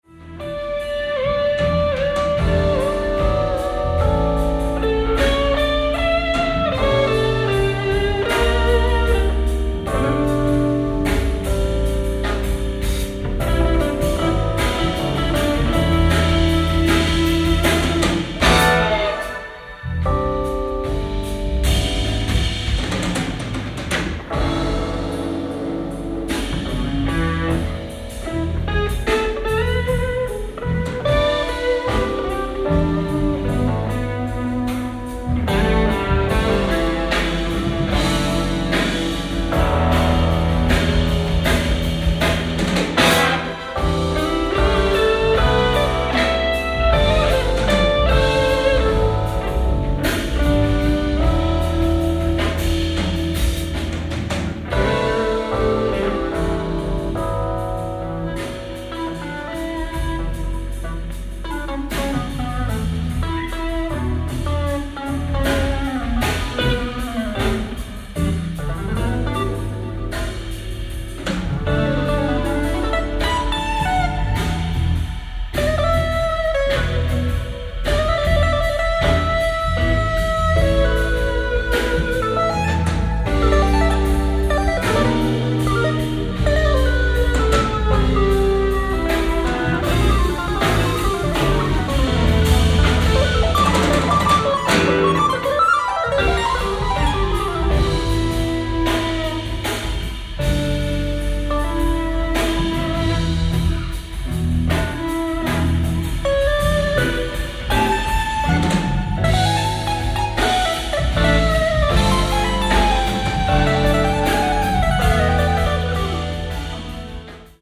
ライブ・アット・ジャズフェスト、グローナウ、ドイツ 04/28/2019
※試聴用に実際より音質を落としています。